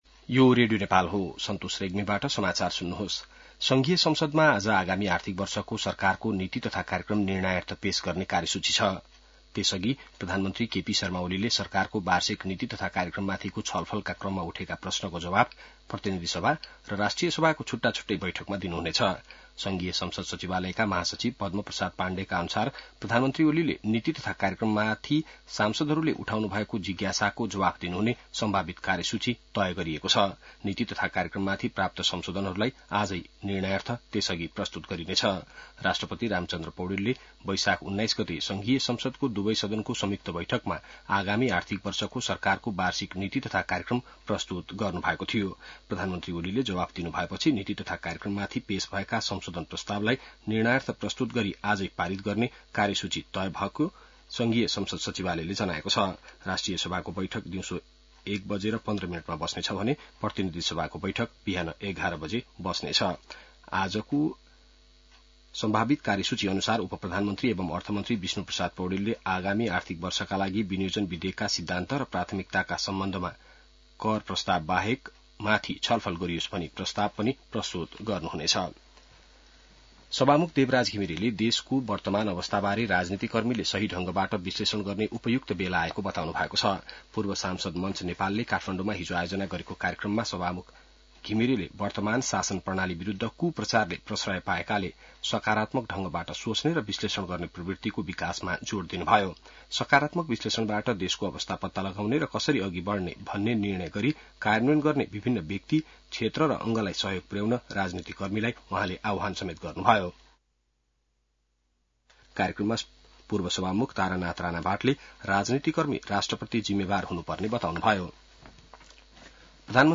बिहान ६ बजेको नेपाली समाचार : २८ वैशाख , २०८२